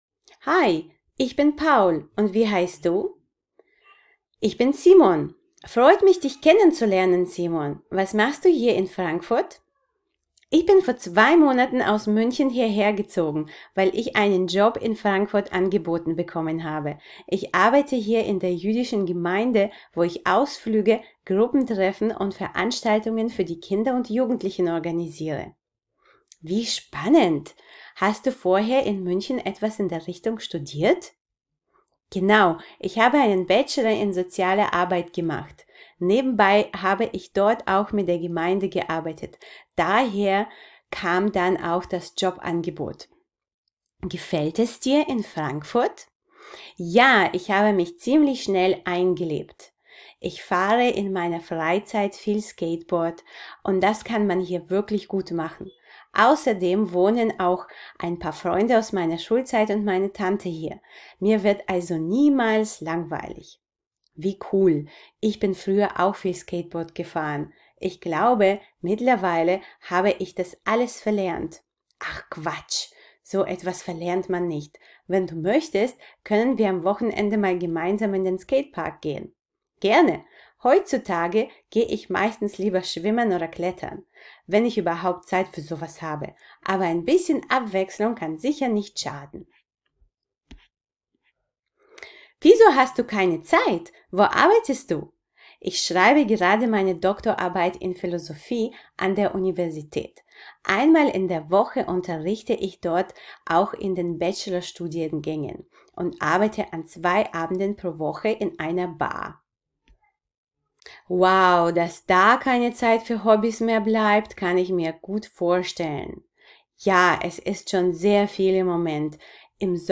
Dialog: Kennenlernen (Audio)